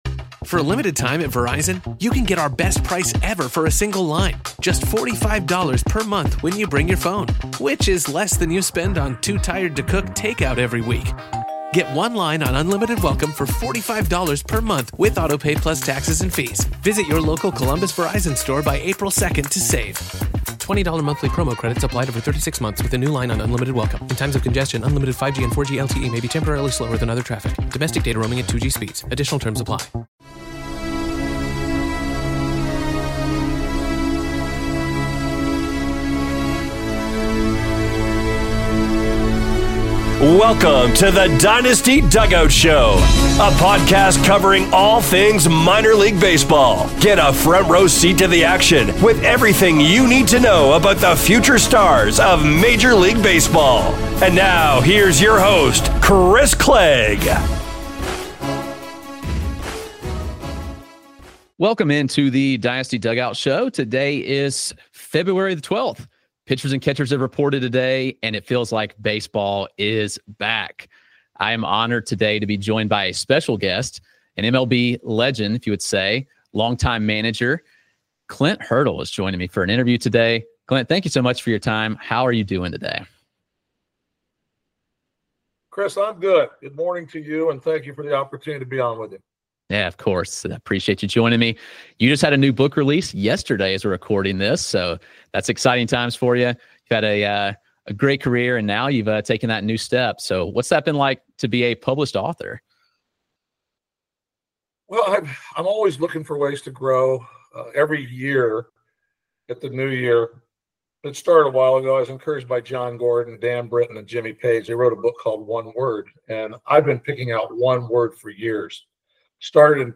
In this special episode of The Dynasty Dugout Show, I sit down with Clint Hurdle, former Colorado Rockies and Pittsburgh Pirates manager.